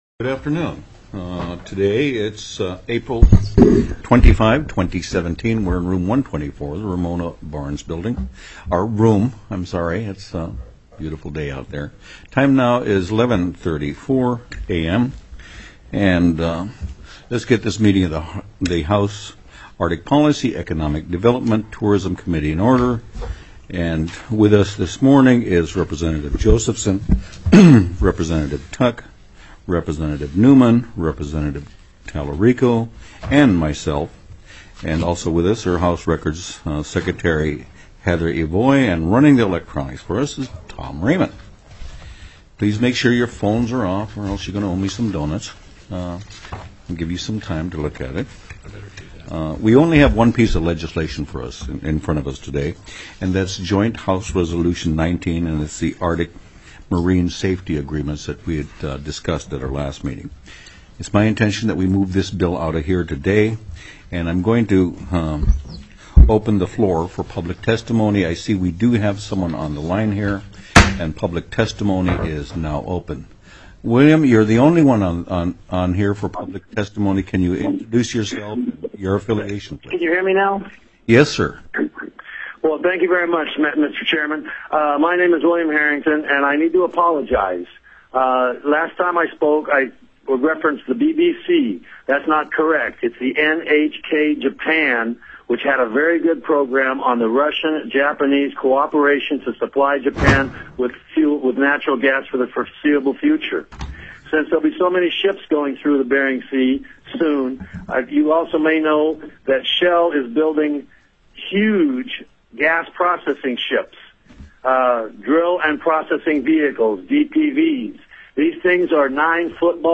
The audio recordings are captured by our records offices as the official record of the meeting and will have more accurate timestamps.
+= HJR 19 ARCTIC MARINE SAFETY AGREEMENTS TELECONFERENCED
-- Public Testimony --